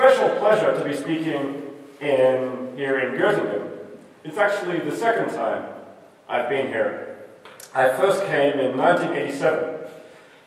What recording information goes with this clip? Can anyone help me with how I would best fix this track so’s the speaker’s voice is more tolerable and understandable? This is the classic case of hall echo, and as far as I know there’s nothing you can do about it.